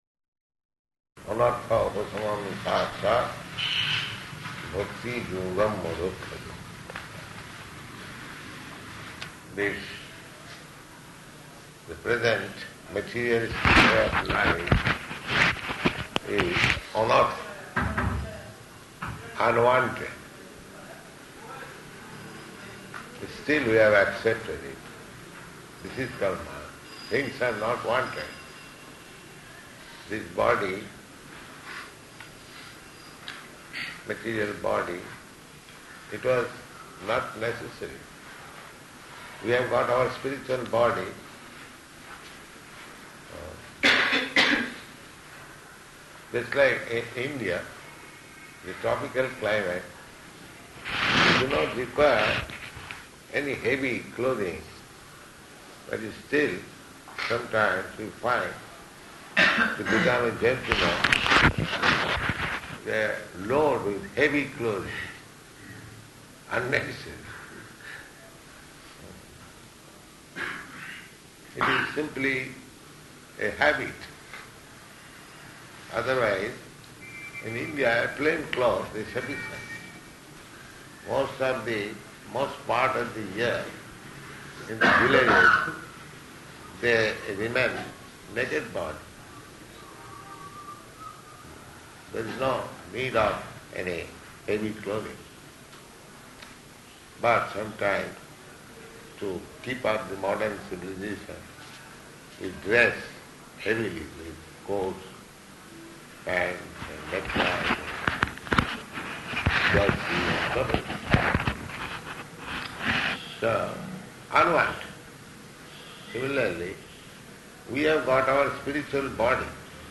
Type: Lectures and Addresses
Location: Delhi